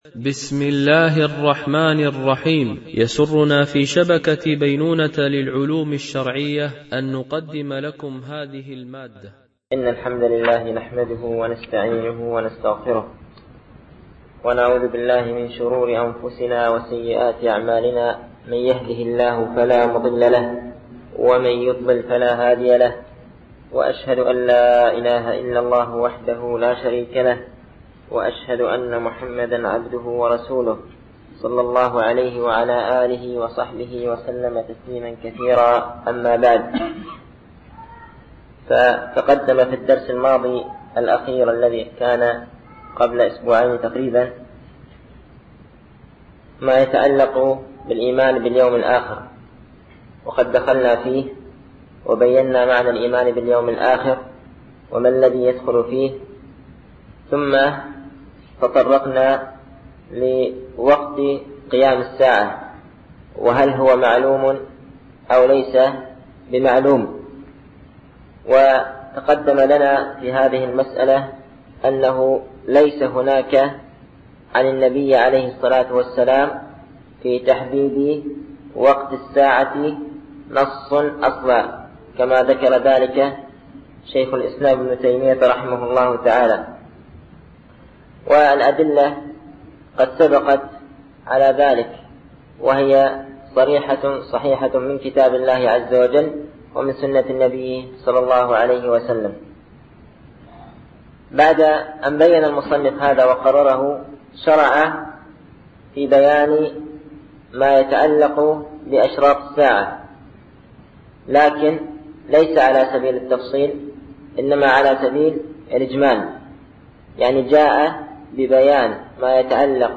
الألبوم: شبكة بينونة للعلوم الشرعية التتبع: 38 المدة: 38:37 دقائق (8.88 م.بايت) التنسيق: MP3 Mono 22kHz 32Kbps (CBR)